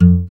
Index of /90_sSampleCDs/Sound & Vision - Gigapack I CD 2 (Roland)/GUI_ACOUST. 32MB/GUI_Acoust. Slap